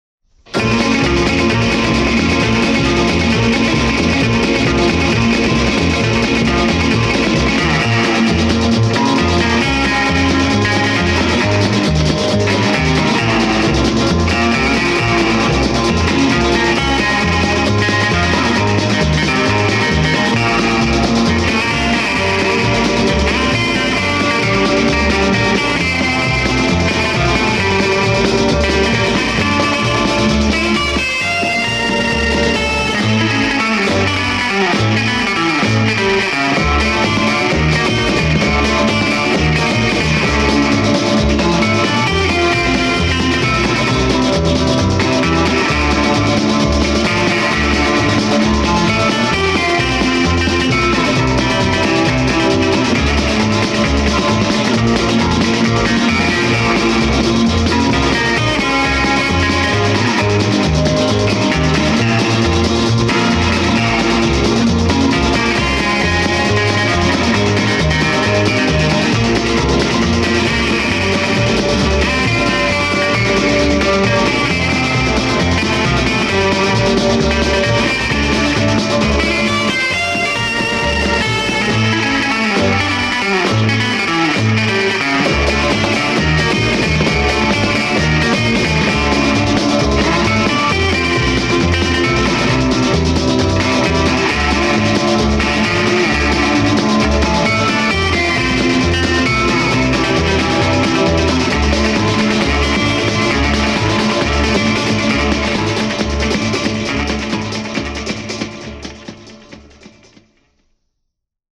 инструментальная группа 60-х годов XX века.